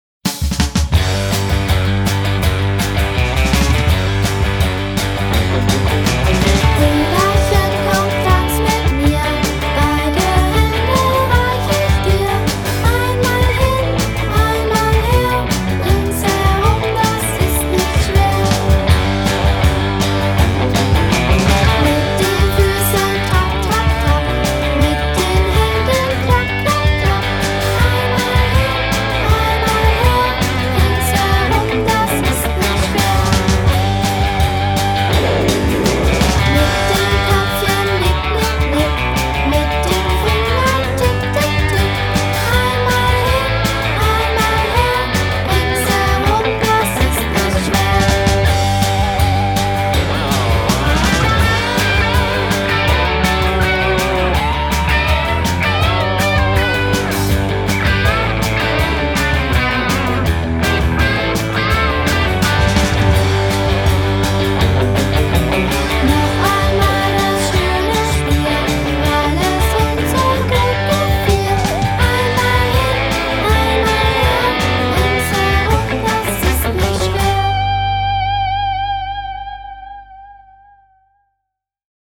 Kinderparty